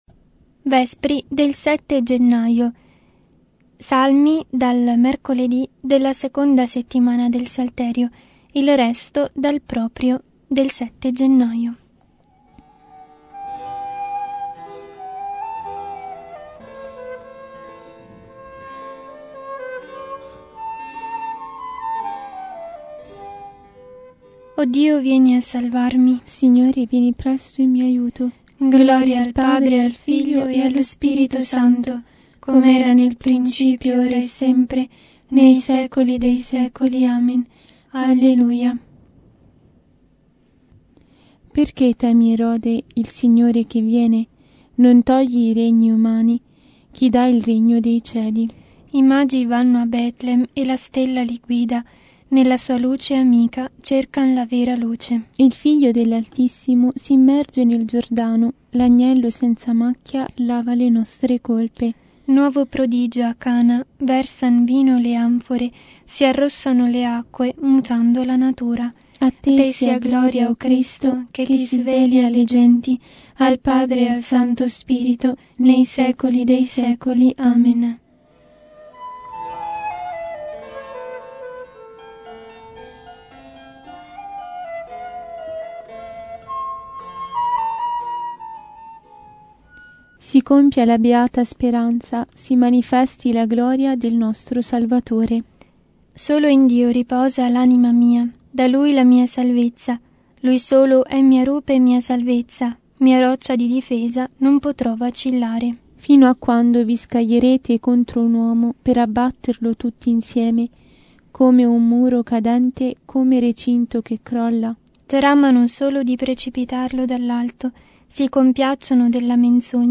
Vespri – 7 Gennaio